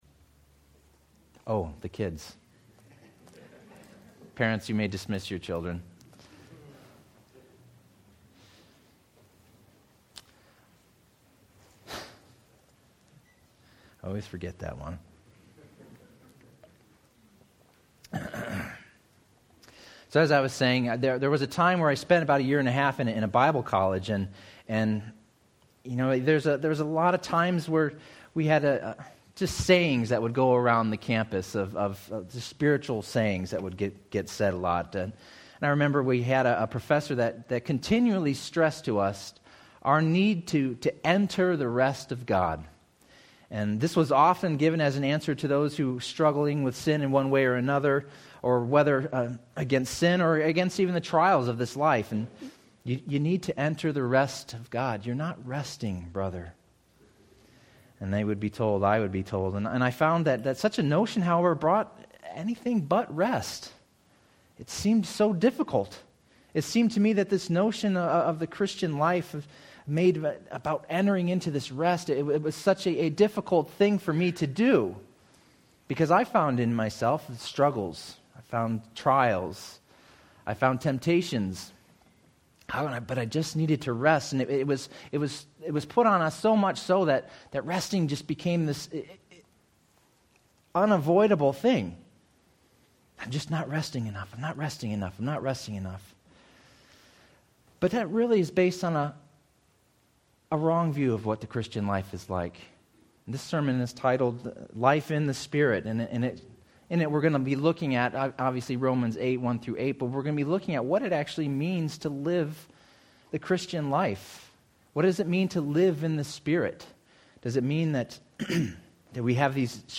Audio Notes All sermons are copyright by this church or the speaker indicated.